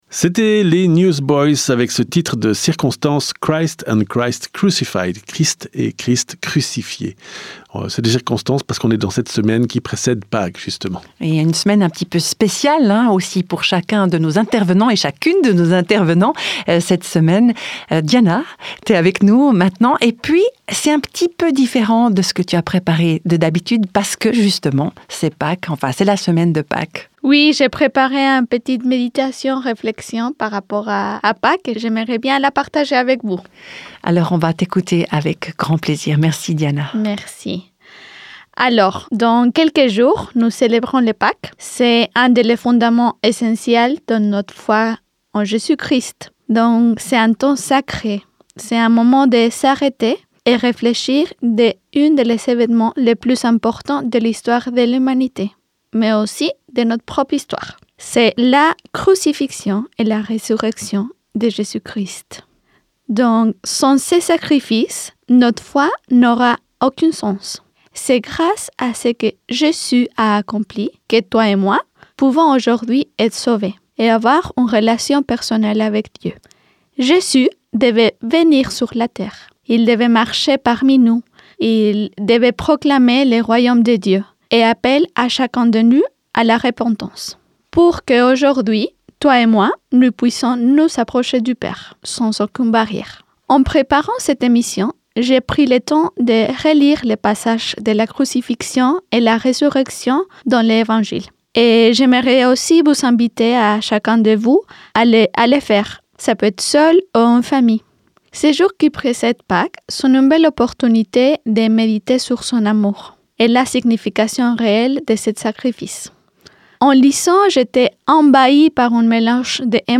réflexion méditative